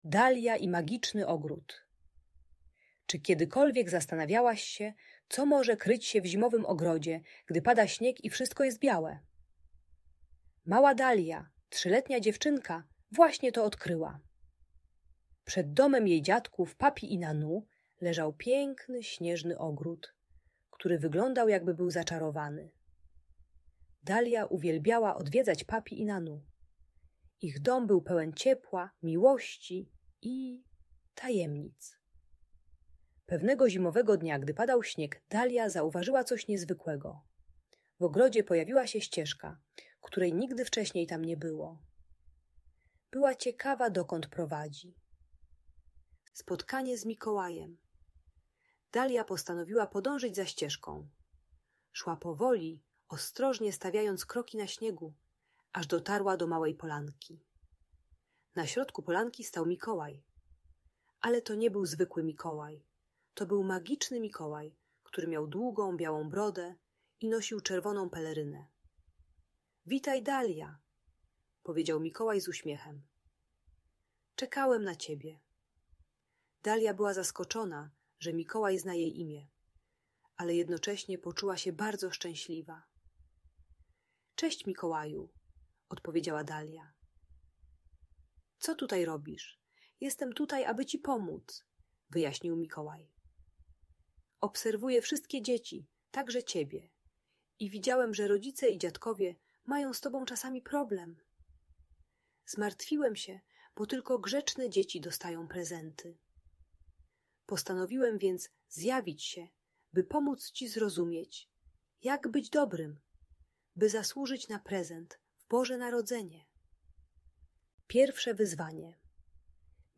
Dalia i magiczny ogród - Niepokojące zachowania | Audiobajka